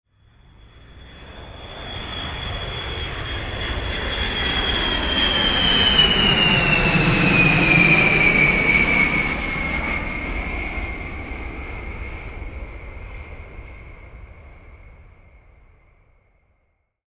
Bomber Pass By